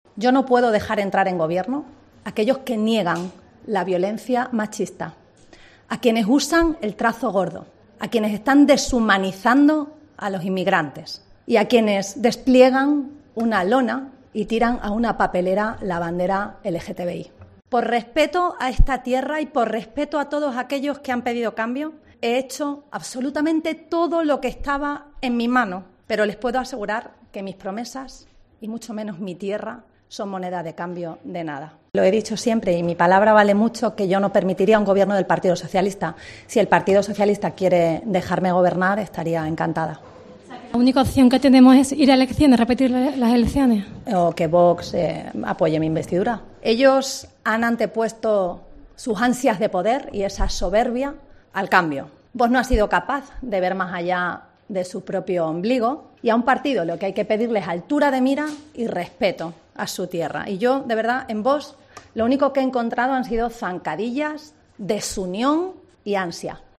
María Guardiola, durante el Pleno de la Asamblea